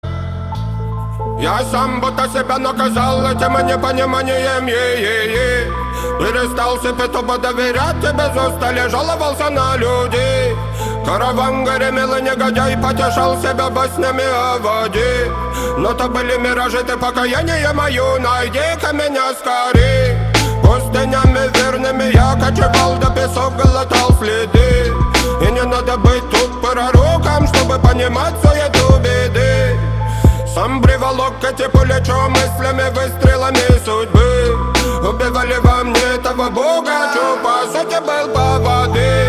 • Качество: 320, Stereo
Хип-хоп
грустные
спокойные